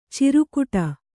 ♪ cirukuṭa